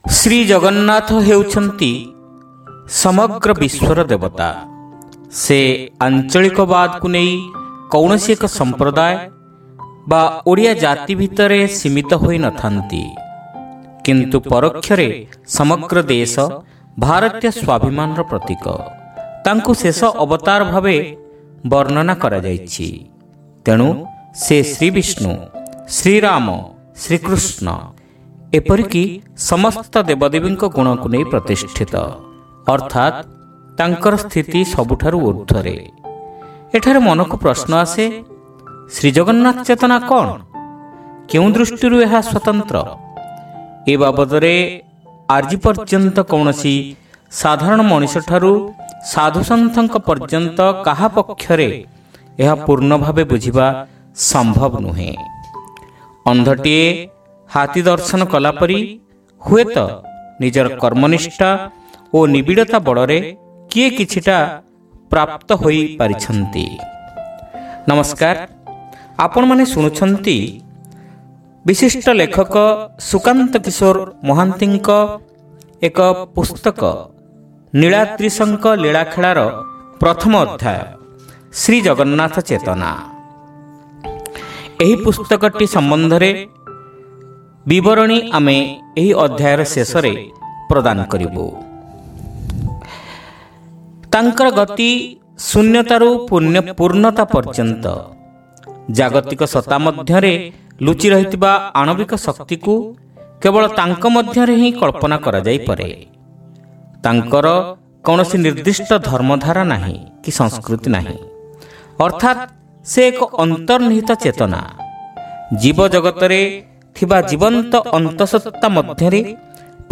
ଶ୍ରାବ୍ୟ ଗଳ୍ପ : ଶ୍ରୀଜଗନ୍ନାଥ ଚେତନା